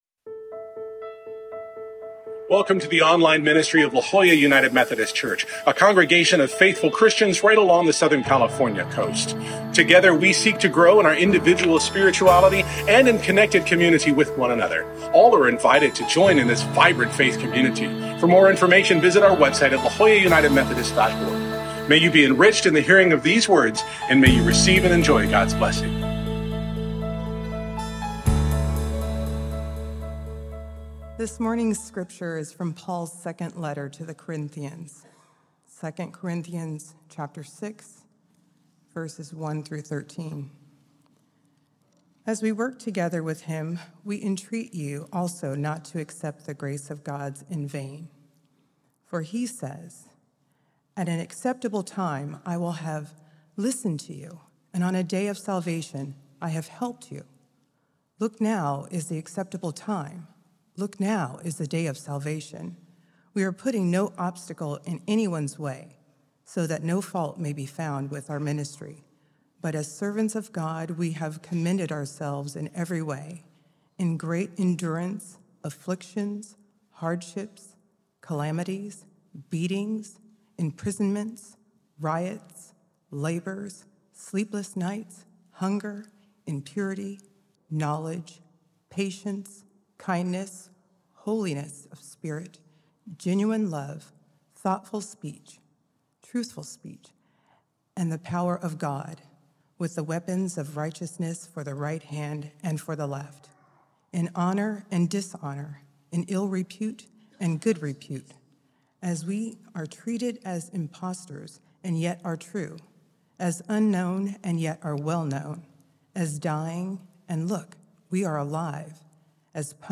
This week we begin a new sermon series inviting us to explore how we live open-heartedly in a risky world and at a contentious time. Scripture: 2 Corinthians 6:1-13 (NRSVue)